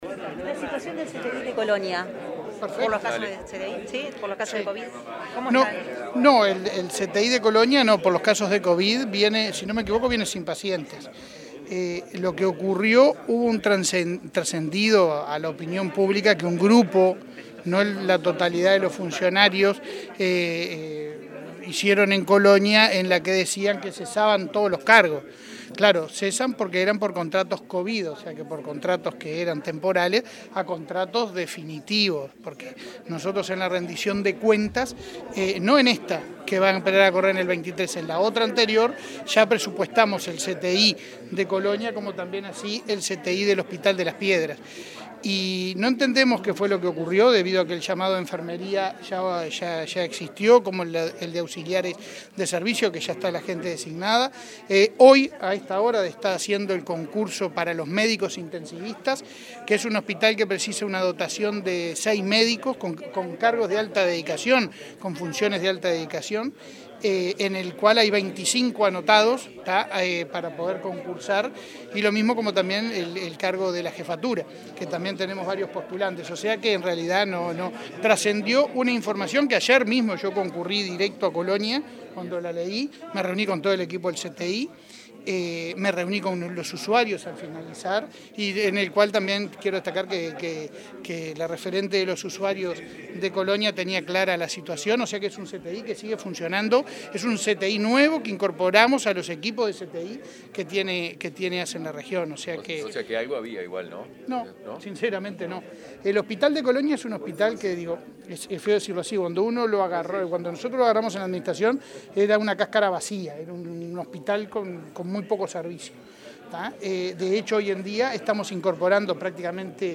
Declaraciones del presidente de ASSE, Leonardo Cipriani
El miércoles 28, el presidente de la Administración de los Servicios de Salud del Estado (ASSE), Leonardo Cipriani, dialogó con medios informativos